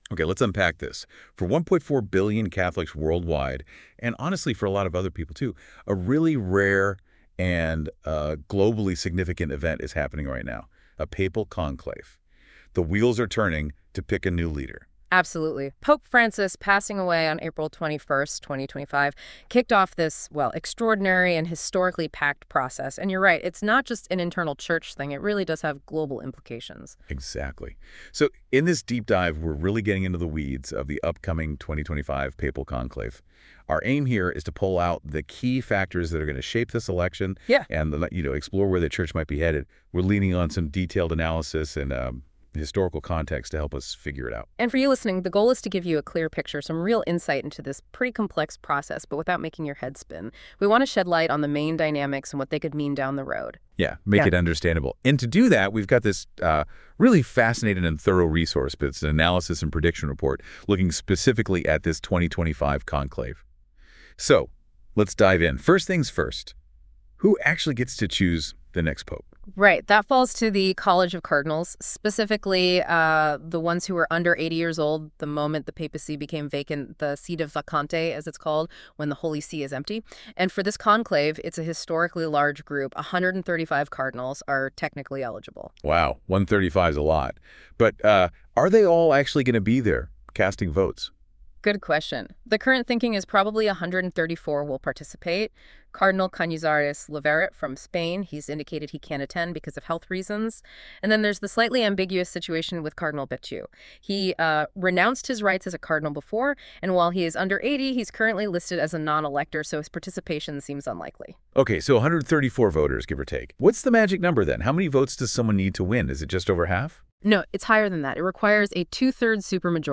Play Rate Listened List Bookmark Get this podcast via API From The Podcast This is a NotebookLM feed that I created to make podcasts.